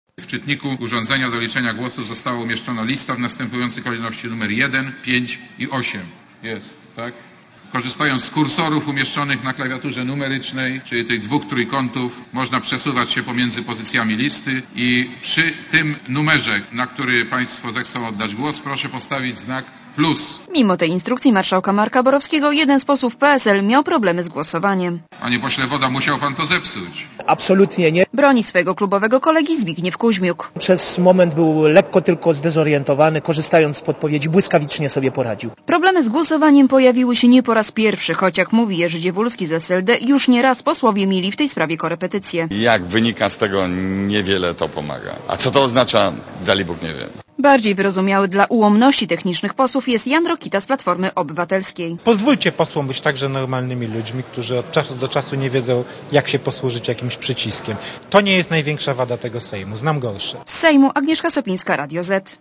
Komentarz audio (244Kb)